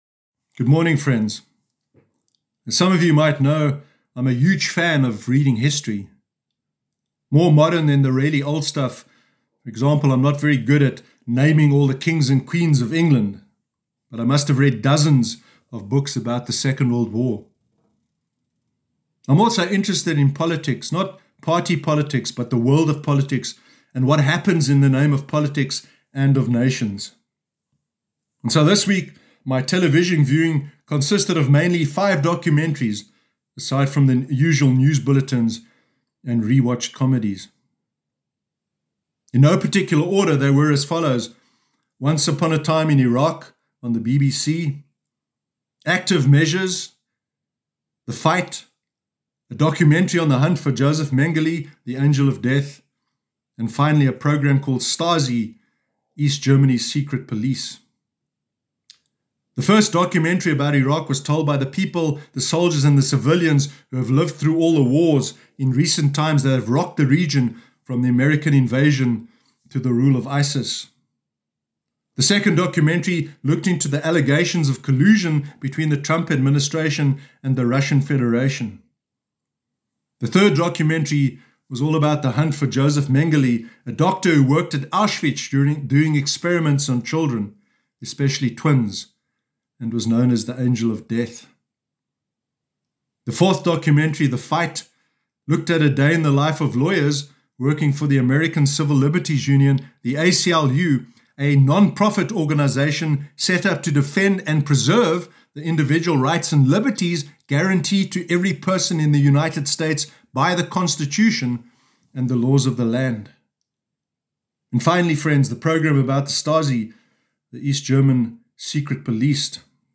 Sermon Sunday 16 August 2020